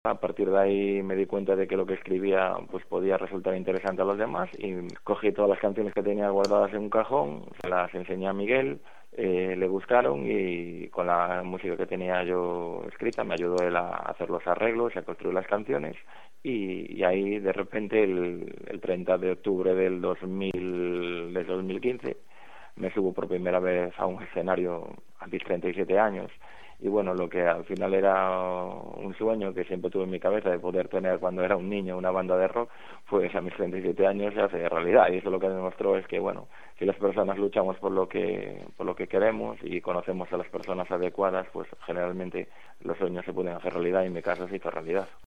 con satisfecha sonrisa al recordar formato MP3 audio(0,86 MB)“Así, de repente, el 30 de octubre de 2015 me subo a un escenario, ¡a los 37 años! Al final, el sueño que siempre tuve en mi cabeza desde que era niño, ¡una banda de rock!, se hace realidad”.